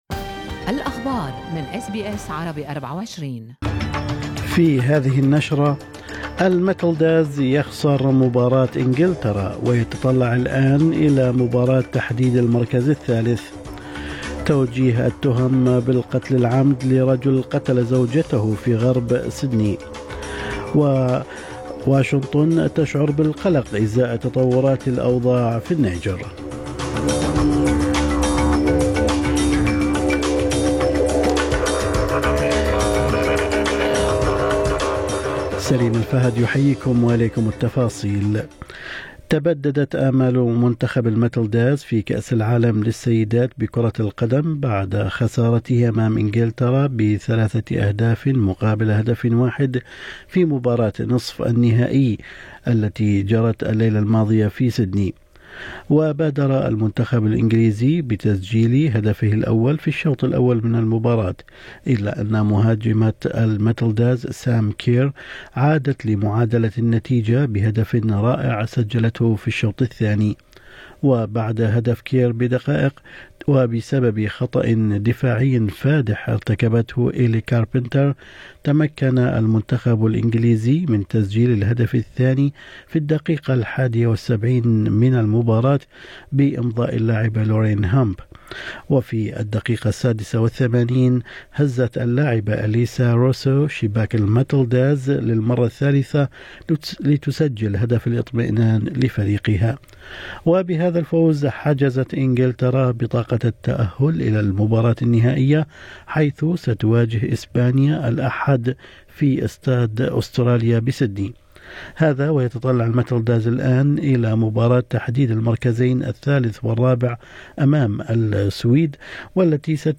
نشرة أخبار الصباح 17/8/2023
يمكنكم الاستماع الى النشرة الاخبارية كاملة بالضغط على التسجيل الصوتي أعلاه.